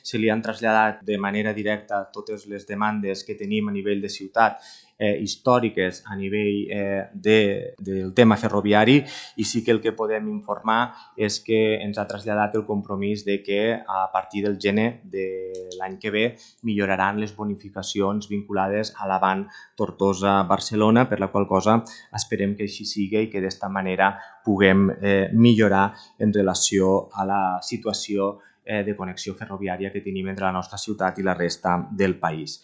Així ho explicava l’Alcalde Jordi Jordan en el decurs del ple ordinari d’aquest dilluns…